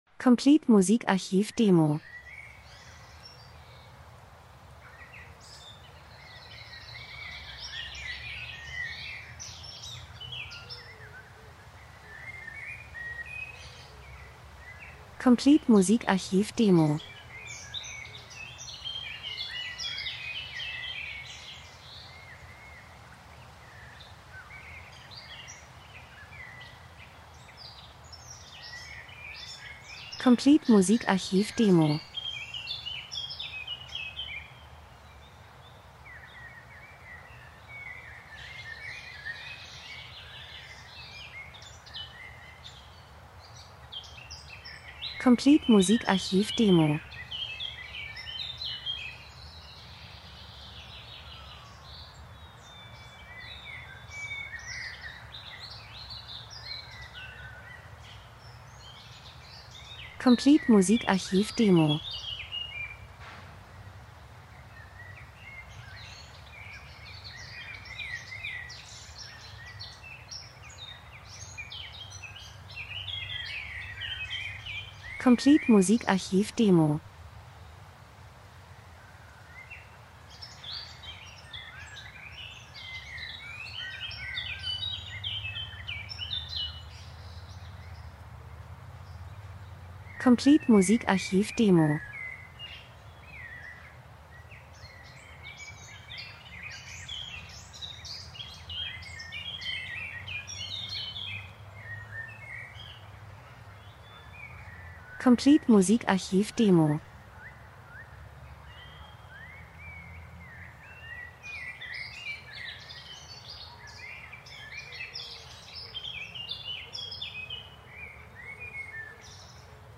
Frühling -Geräusche Soundeffekt Natur Vögel Wind Wald 02:04